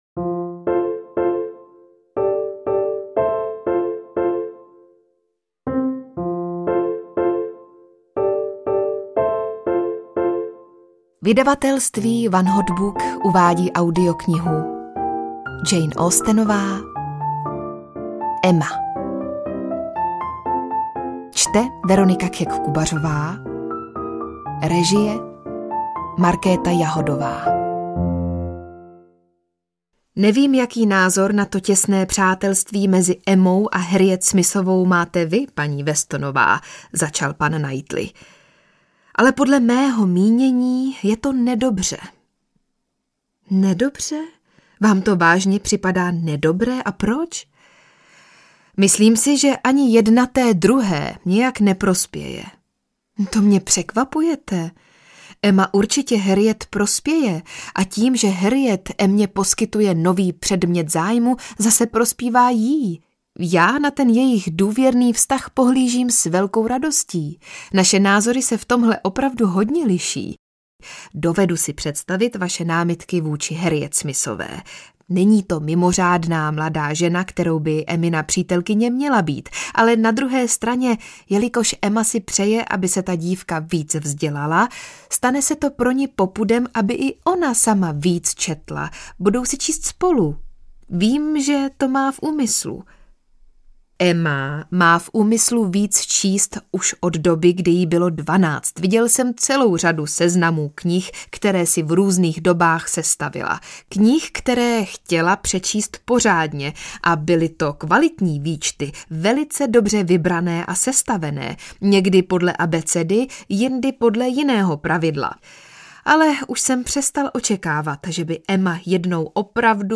Emma audiokniha
Ukázka z knihy
• InterpretVeronika Khek Kubařová